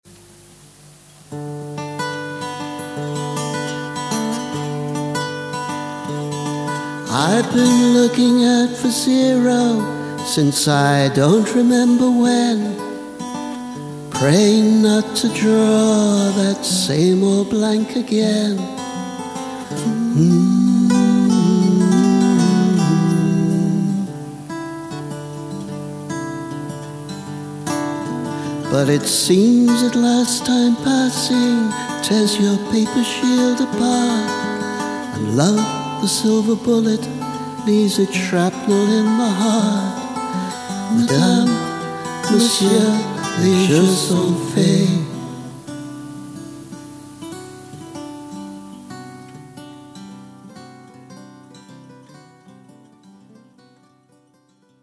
In fact, it tends to get more complicated. Sketch for an arrangement.